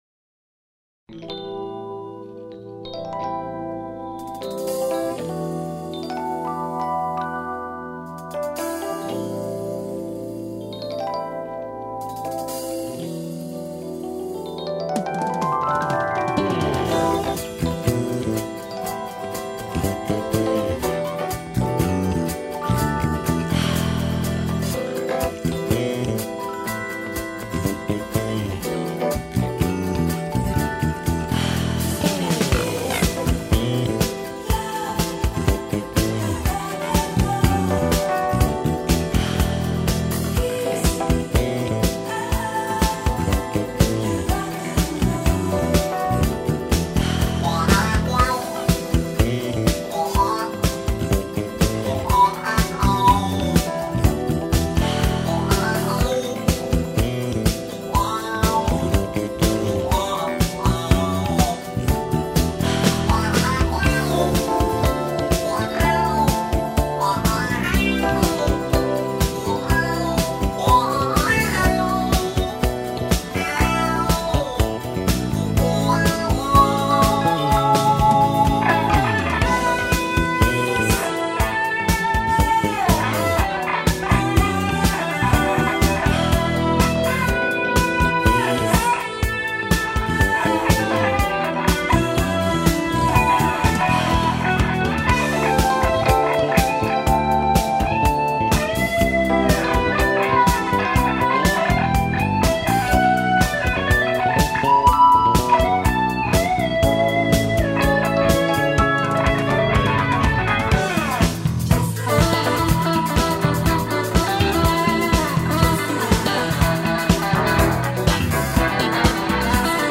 drums
bass guitar
multi-guitar arrangements